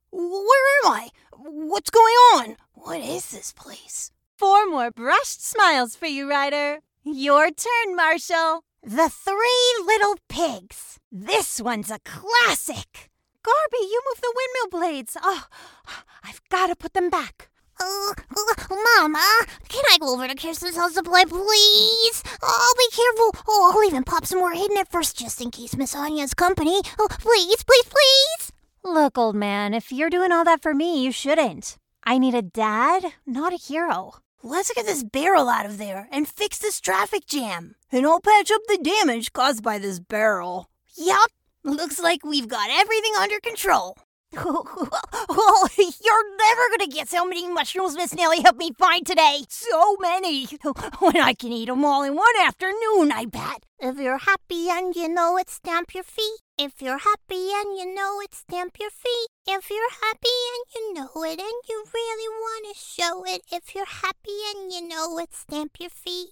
Animação
Sou especialista em leituras comerciais autênticas e coloquiais com um tom caloroso e jovem. Fui descrita como tendo uma qualidade vocal amigável e relacionável.